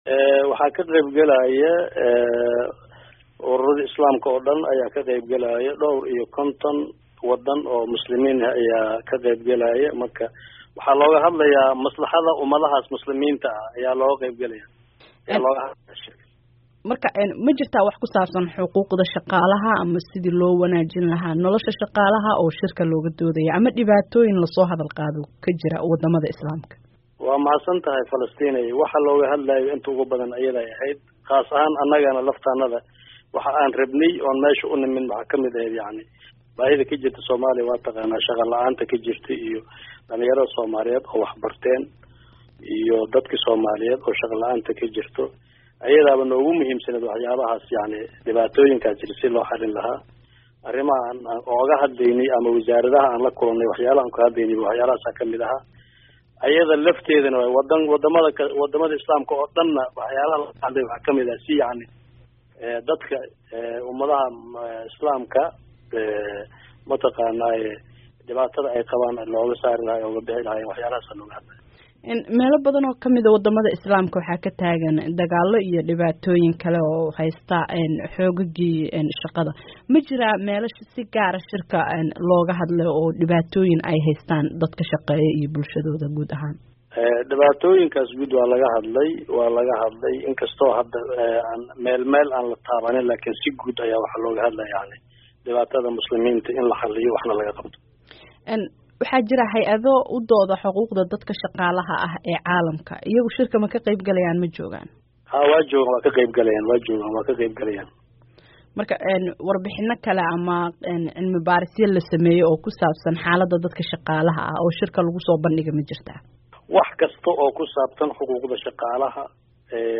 Dhageyso wareysiga wasiirka shaqada